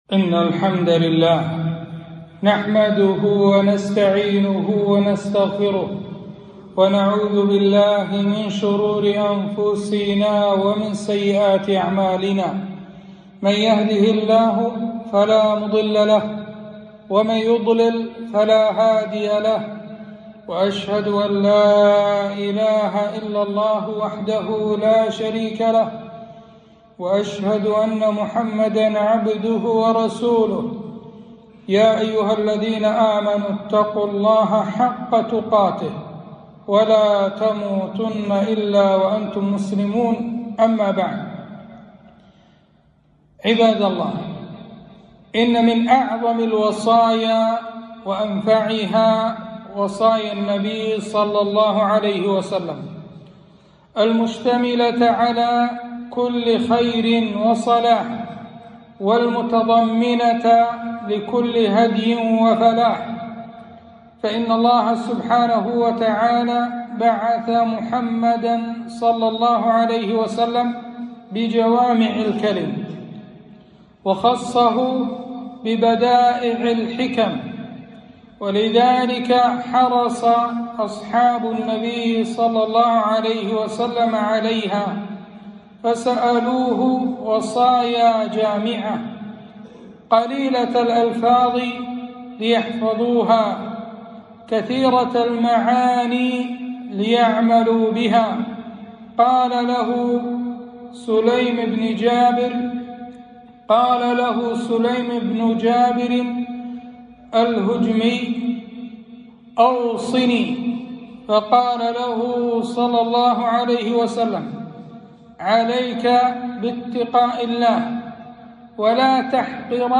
خطبة - أوصاني خليلي بثلاث